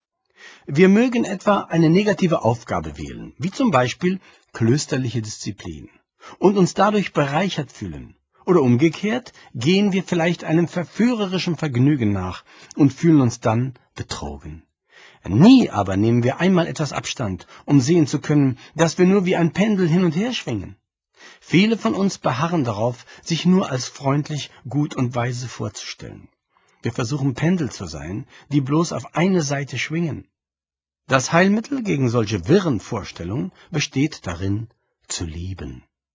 Hörbuch, 68 Minuten
Gelesen von Christian Anders